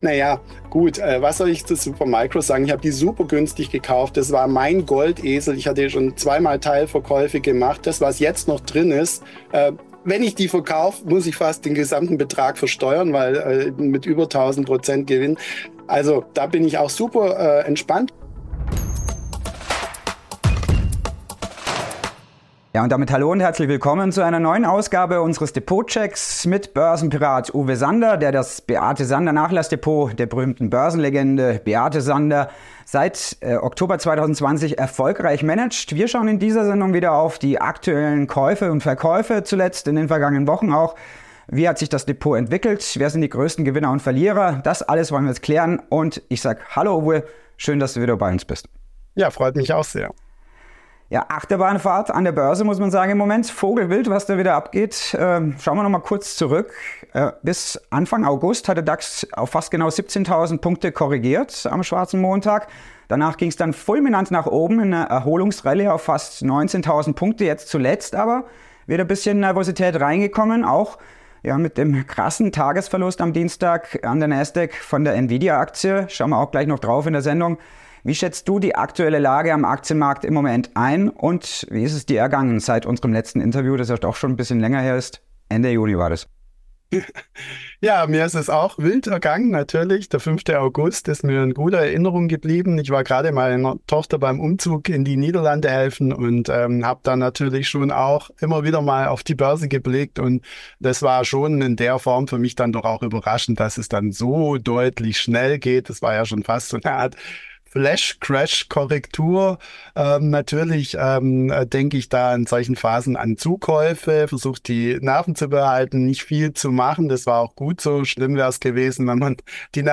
Finanzexperten im Interview